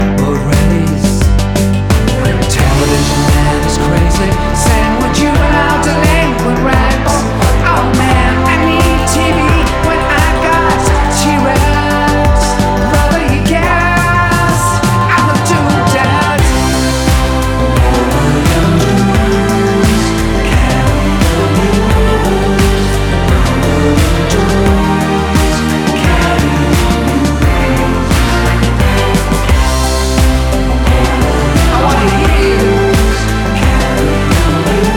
Жанр: Электроника / Альтернатива